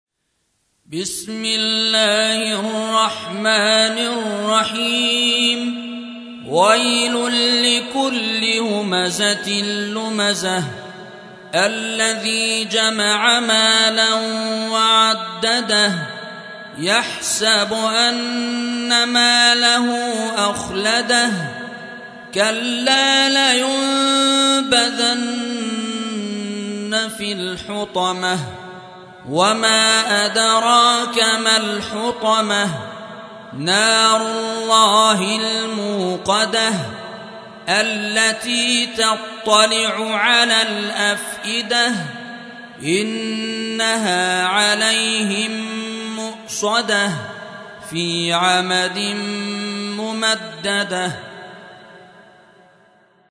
104. سورة الهمزة / القارئ